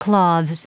Prononciation du mot : cloths